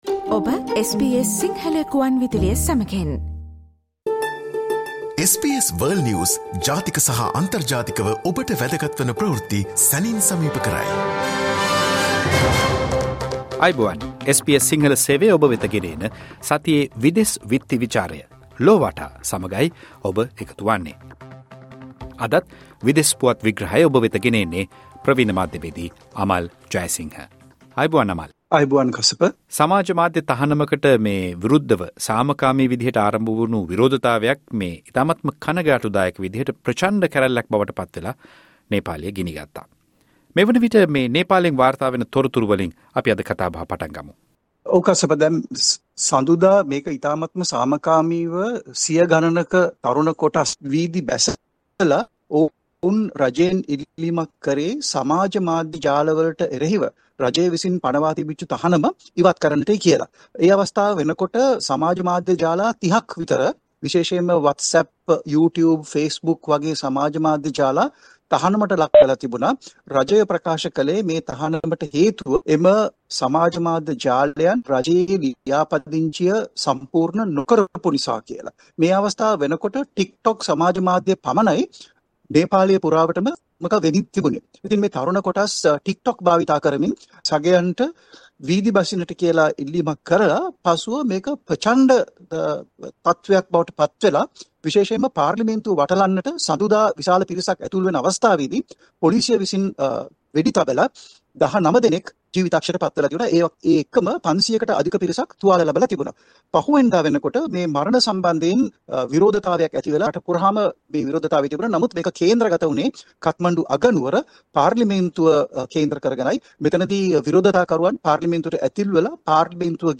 සතියේ විදෙස් පුවත් විග්‍රහය